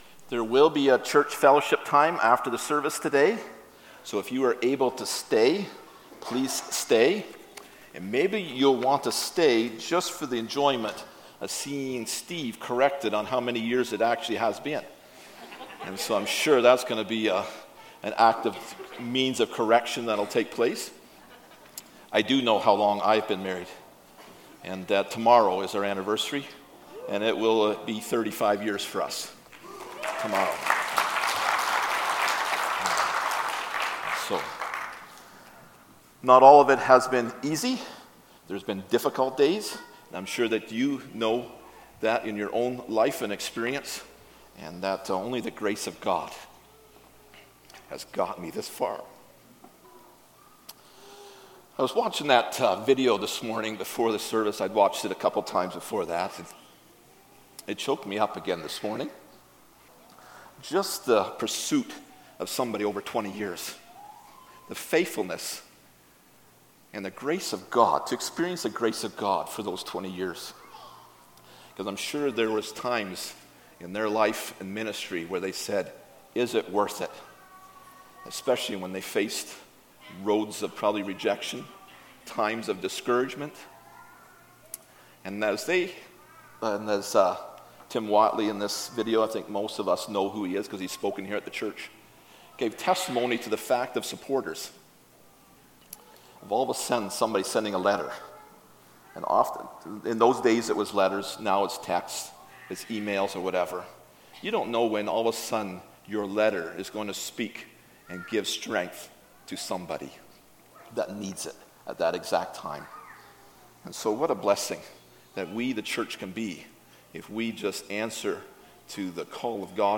John 8:12-20 Service Type: Sunday Morning « Food for the Soul I Am